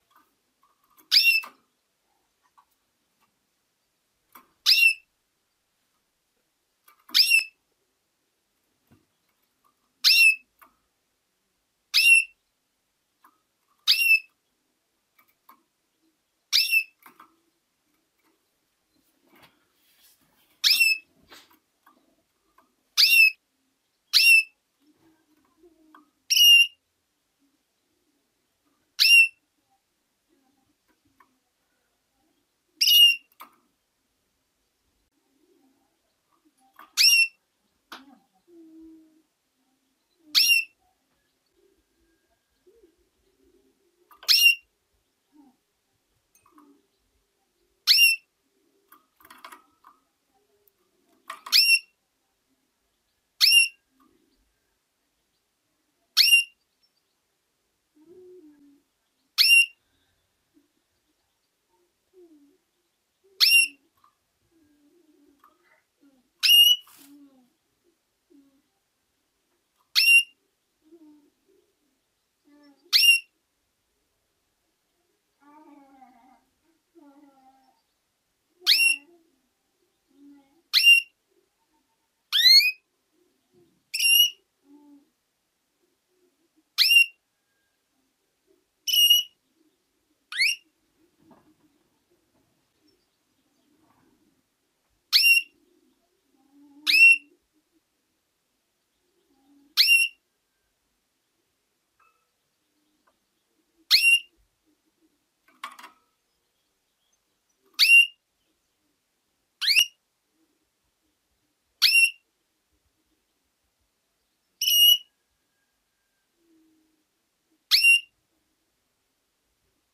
دانلود صدای قناری مست اماده برای جفت برای تحریک از ساعد نیوز با لینک مستقیم و کیفیت بالا
جلوه های صوتی